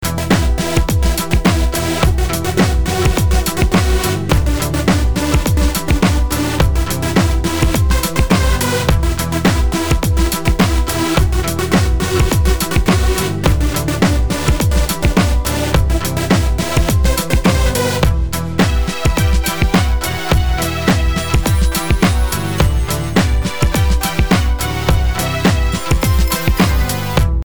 Вот для примера замастерил под розовый шум фрагмент при помощи полочника URS.